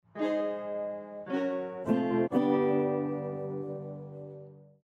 correctB.ogg